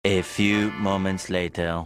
A Few Moment Later A Few Moment Later sound buttons A Few Moment Later sound effect A Few Moment Later soundboard Get Ringtones Download Mp3 Notification Sound